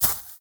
Minecraft Version Minecraft Version latest Latest Release | Latest Snapshot latest / assets / minecraft / sounds / block / nether_sprouts / step4.ogg Compare With Compare With Latest Release | Latest Snapshot
step4.ogg